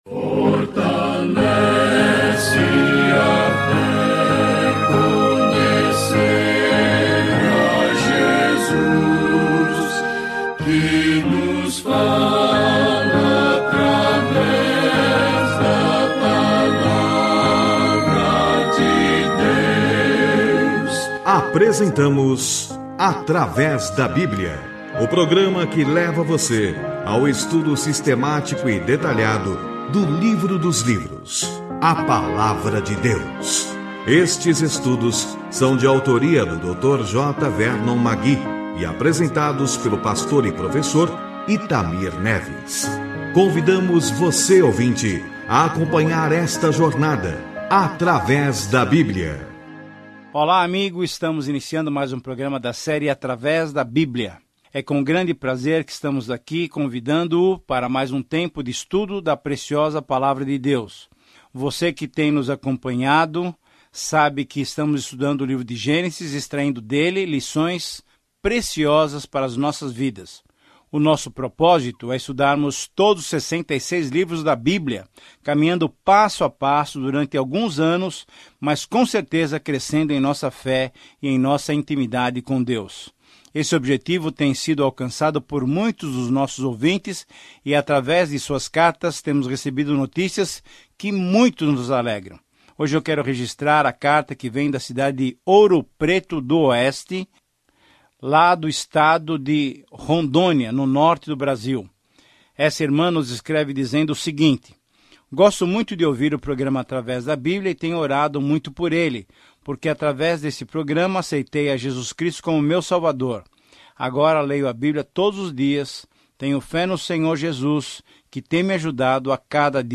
Viaje diariamente por Gênesis enquanto ouve o estudo em áudio e lê versículos selecionados da palavra de Deus no livro de Gênesis.